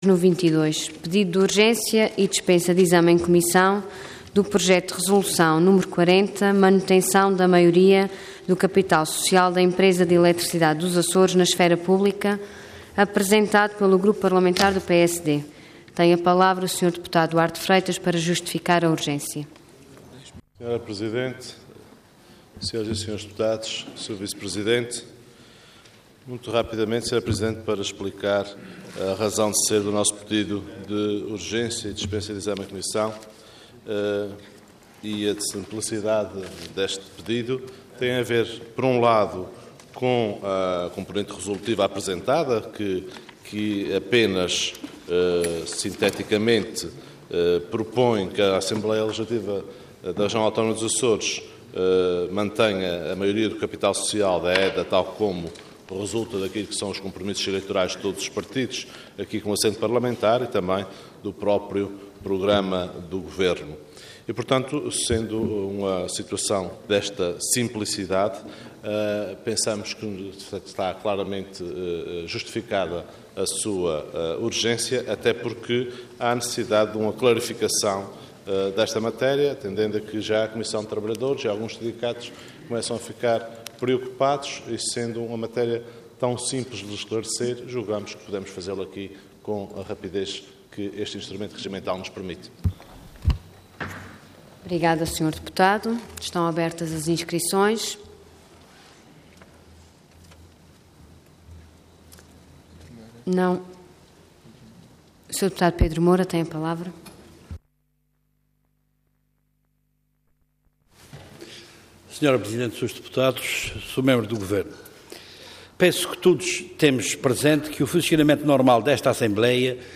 Detalhe de vídeo 21 de junho de 2013 Download áudio Download vídeo Diário da Sessão Processo X Legislatura Manutenção da Maioria do Capital Social da Empresa de Electricidade dos Açores - EDA na Esfera Pública. Intervenção Pedido de urgência seguido de debate Orador Duarte Freitas Cargo Deputado Entidade PSD